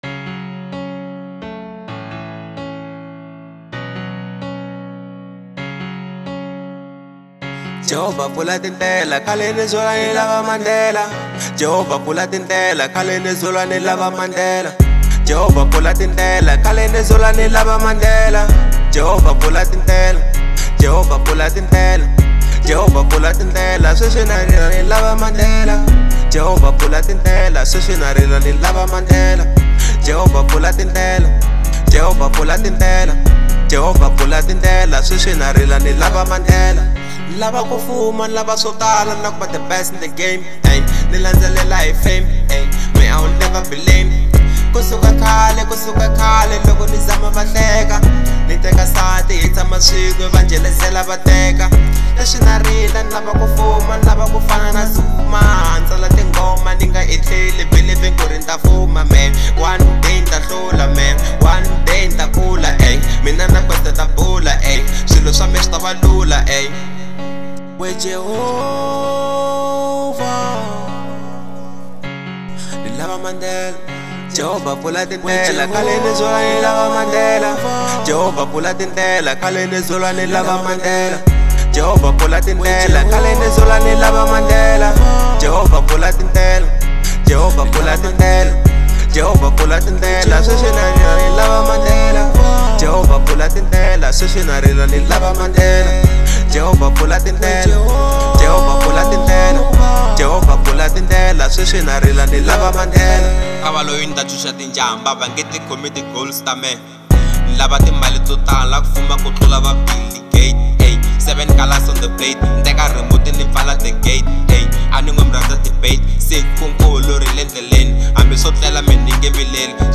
02:51 Genre : Hip Hop Size